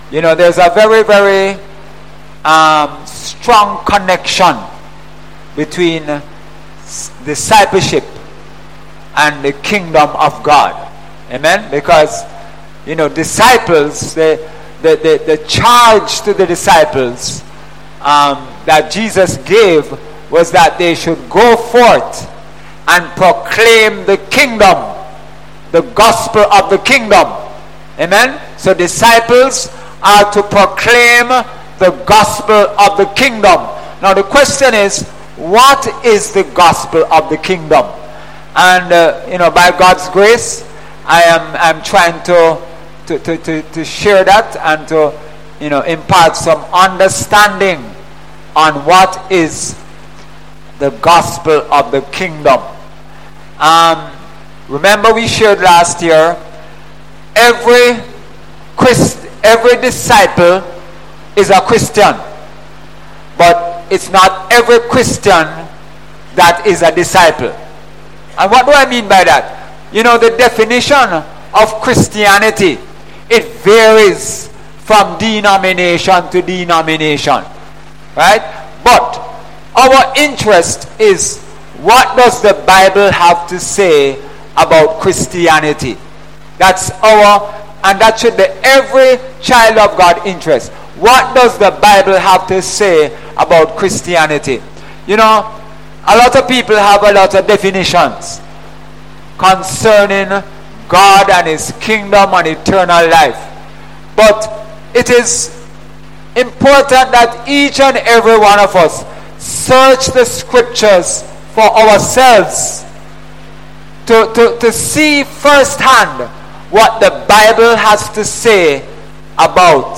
Sunday Sermon – King Jesus and His Kingdom of Power – Jan 21, 2018 | Upper Room Community Church